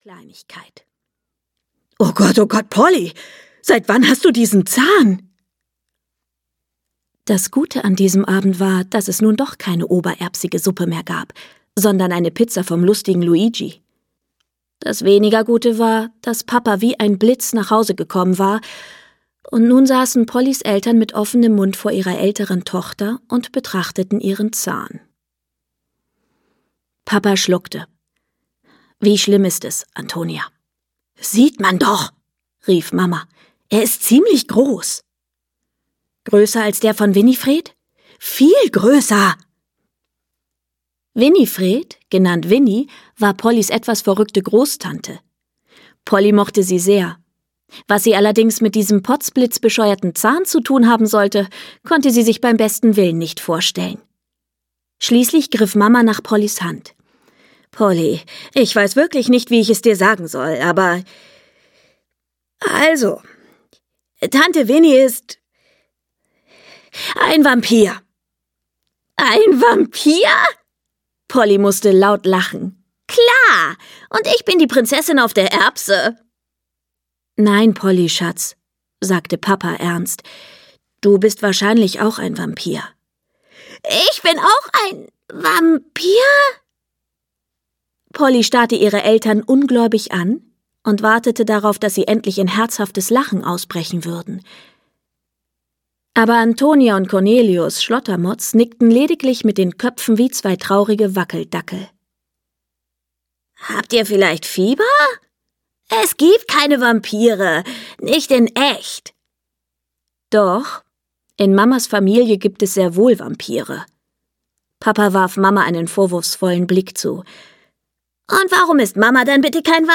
Polly Schlottermotz 1: Polly Schlottermotz - Lucy Astner - Hörbuch